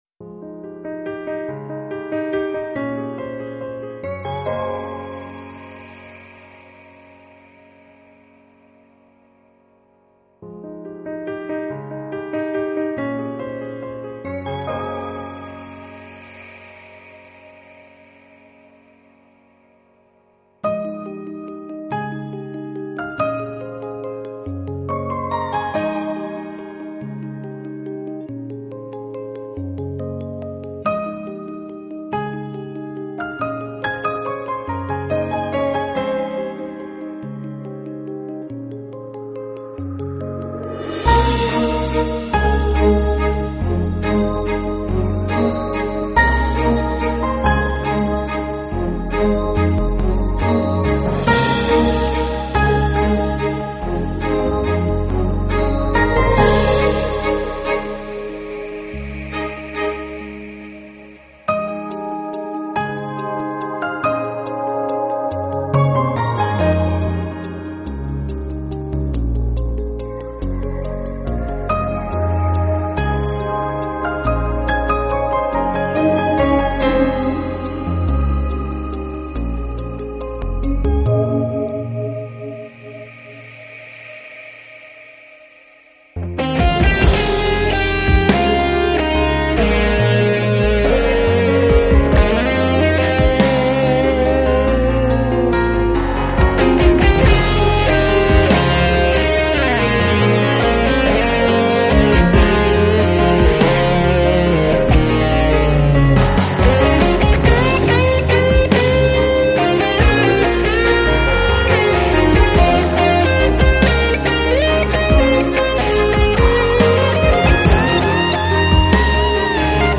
音乐类型：爵士钢琴